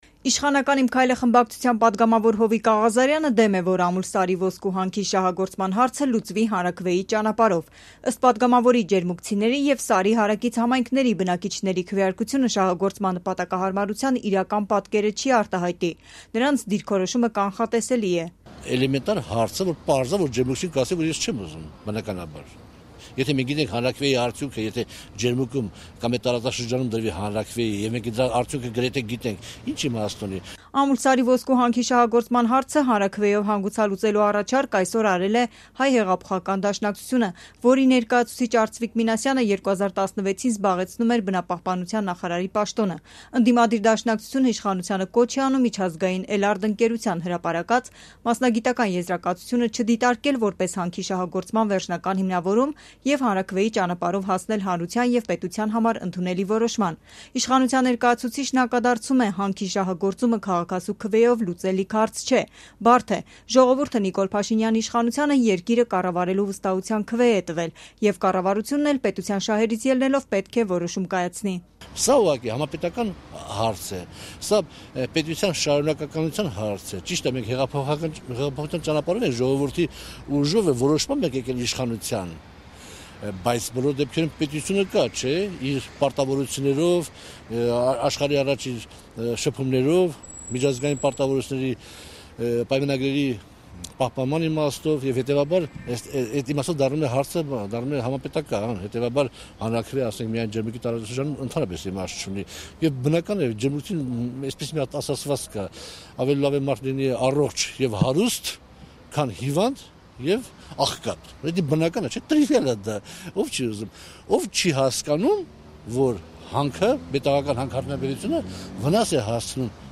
Արդյոք ճիշտ է Ամուլսարի հարցը հանրաքվեով լուծել․ մեկնաբանում են քաղաքական գործիչները
Ռեպորտաժներ